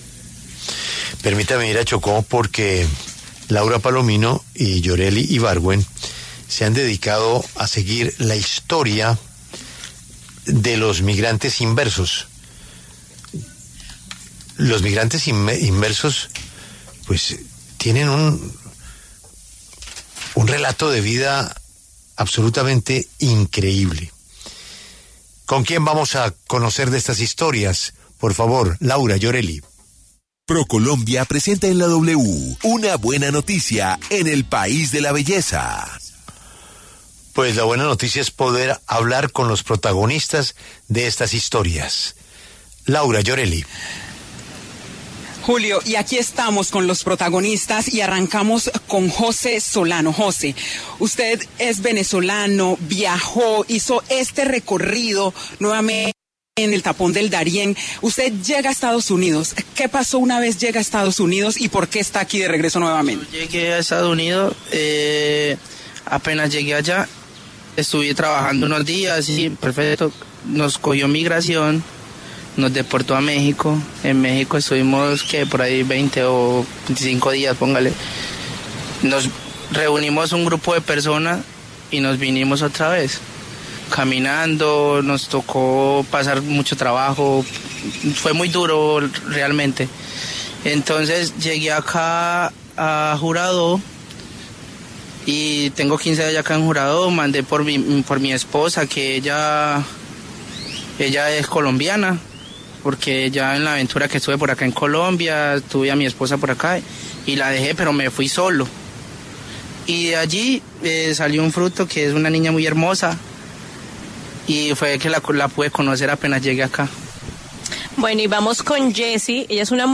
Una serie de migrantes inversos pasaron por los micrófonos de La W para compartir sus experiencias en el proceso de migración, su llegada al municipio de Juradó (Chocó) y su situación actual solicitando apoyo del Gobierno Nacional.